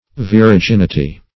Viraginity \Vi`ra*gin"i*ty\, n. The qualities or characteristics of a virago.